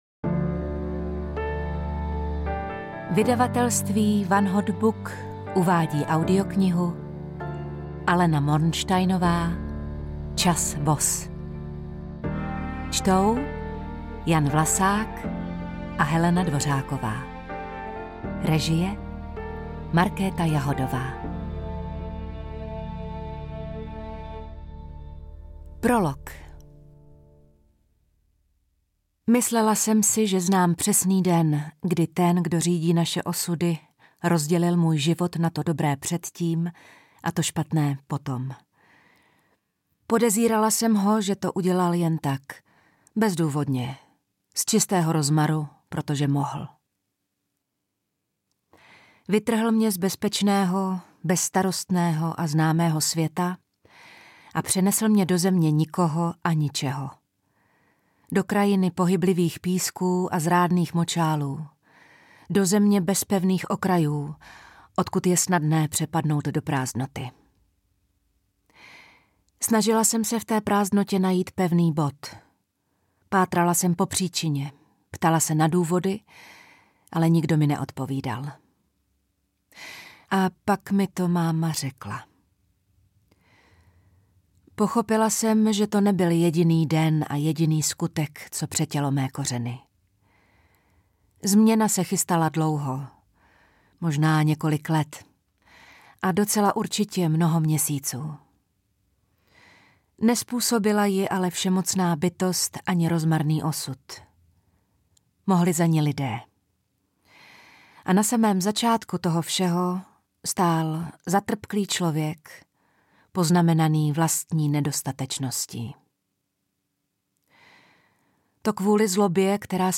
Čas vos audiokniha
Ukázka z knihy
• InterpretHelena Dvořáková, Jan Vlasák